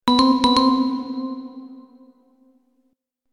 bell.mp3